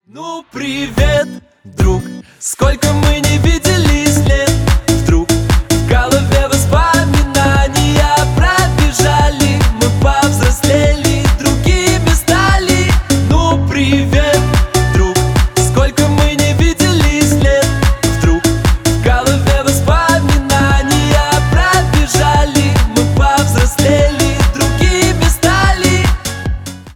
Поп Музыка
клубные # весёлые